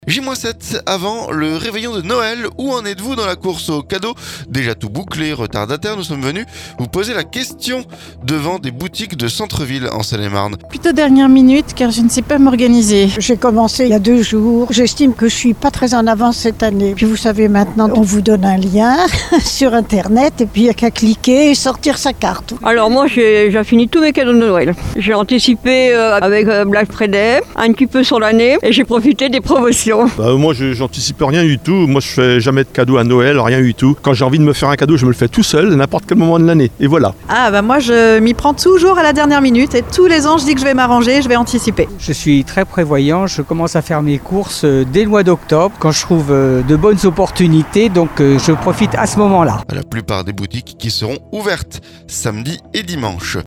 CADEAUX - Où en êtes-vous à une semaine du réveillon? Micro tendu en centre-ville en Seine-et-Marne
Nous sommes venus poser la question à ces passants devant des boutiques de centre-ville en Seine-et-Marne.